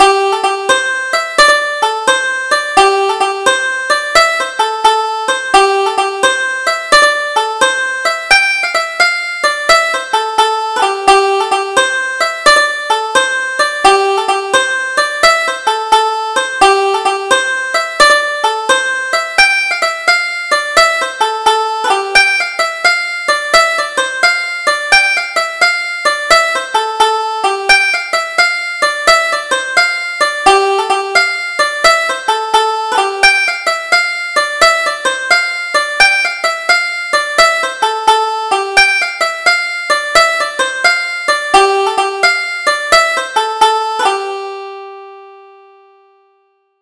Double Jig: Huish the Cat